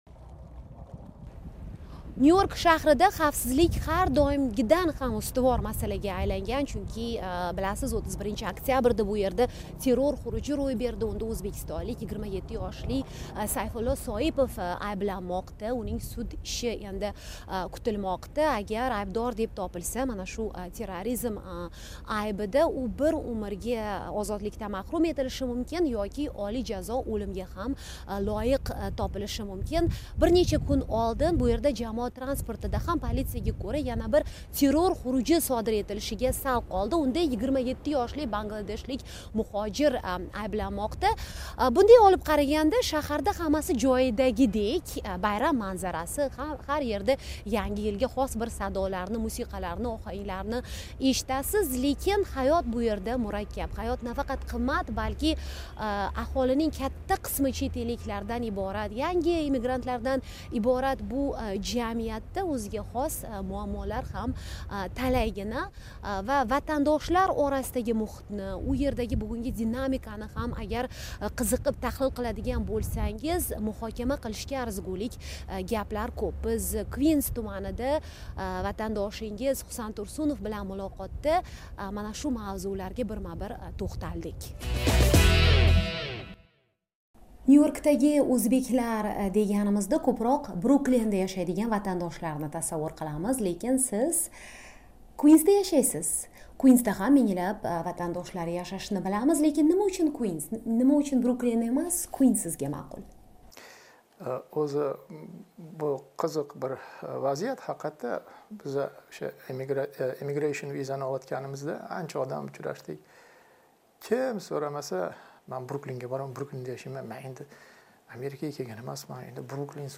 Amerikadagi o'zbeklar nimaga qodir? Ular birlashishi shartmi? Nyu-Yorkda